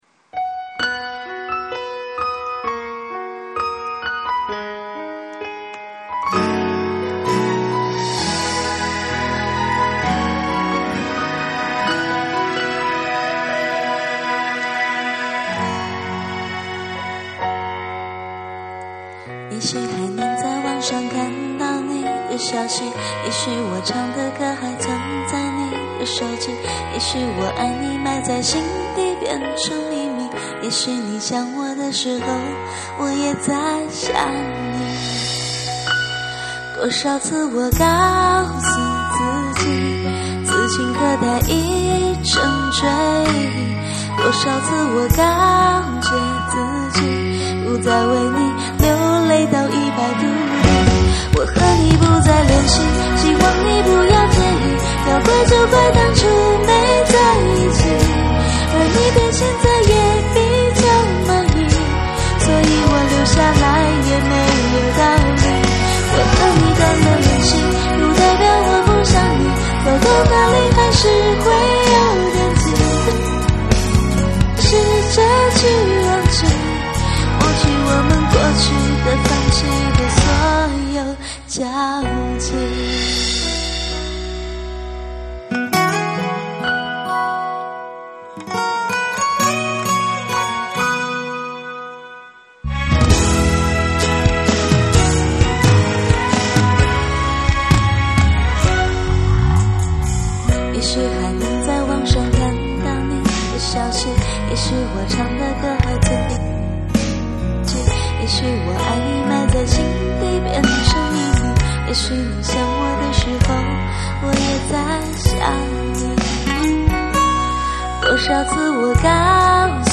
女版